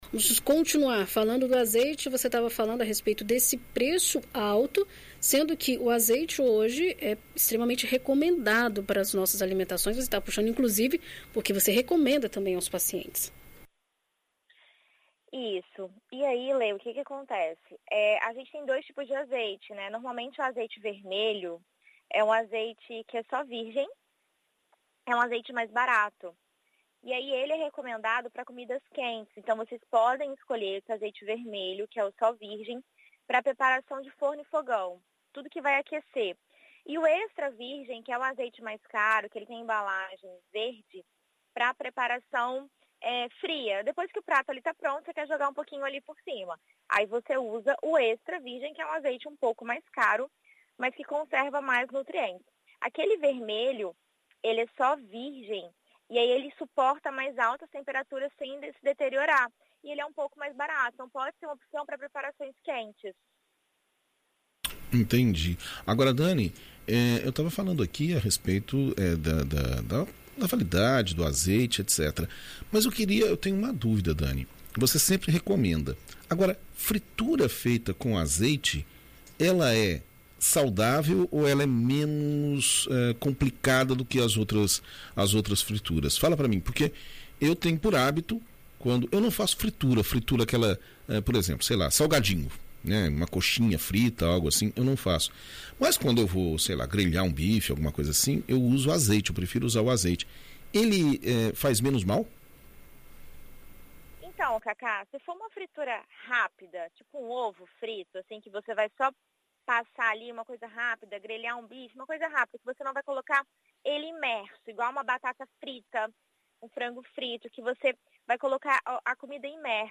Nutricionista ensina como escolher